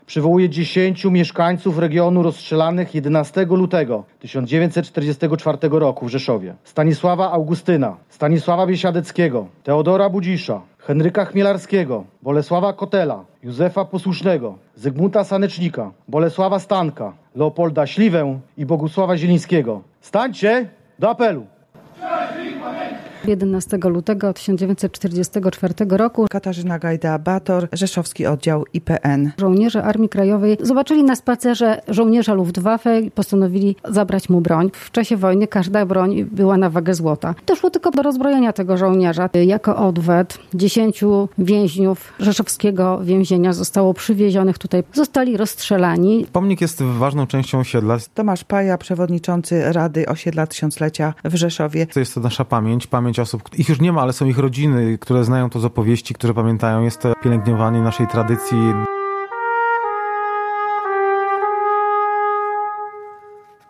Dzisiaj, podczas uroczystości przy monumencie, zwanym pomnikiem „Dziesięciu Rąk”, odbyła się modlitwa, był Apel Pamięci i salwa honorowa: